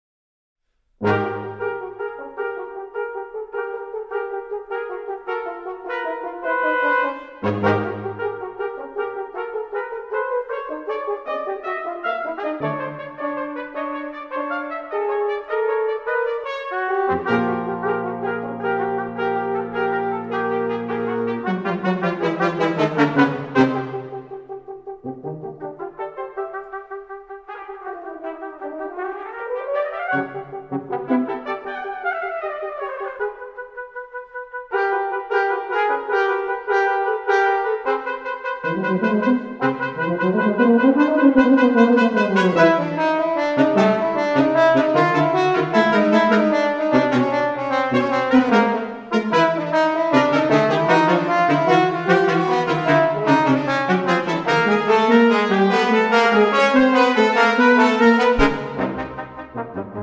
Trumpet
Horn
Trombone
Euphonium